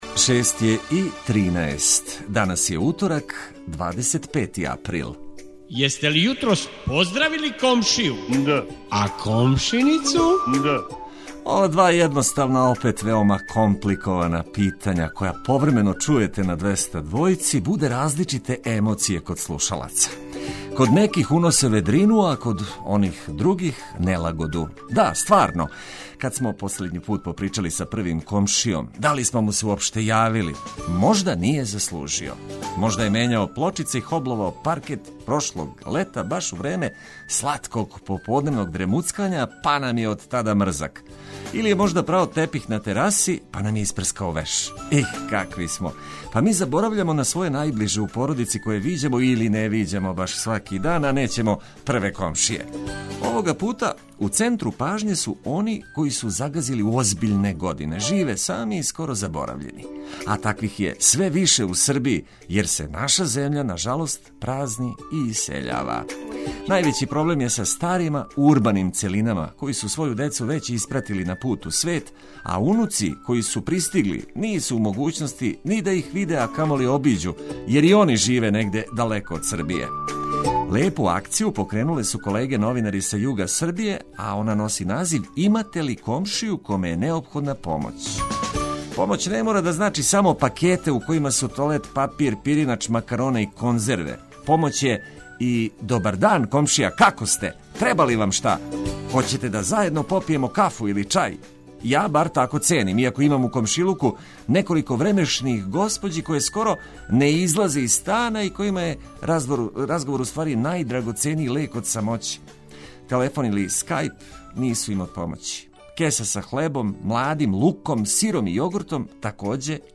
Током јутра кратке, али битне информације о приликама у Србији уз ведру музику која ће олакшати буђење.